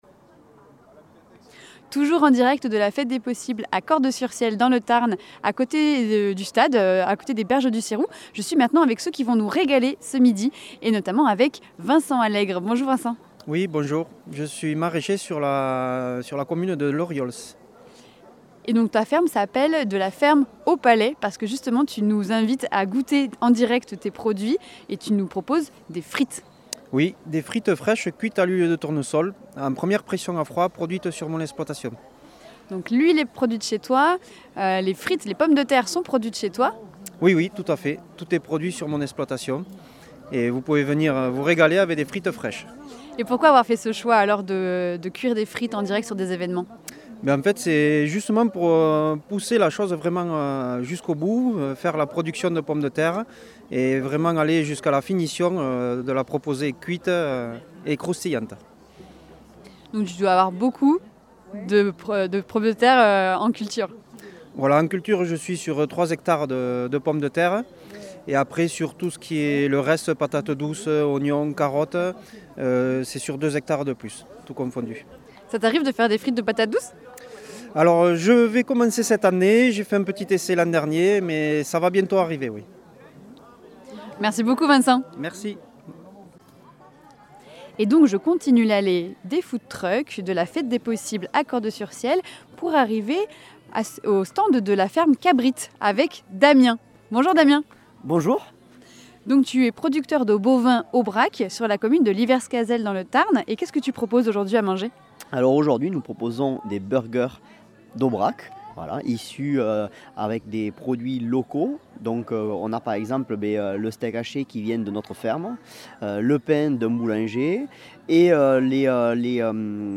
Invité(s)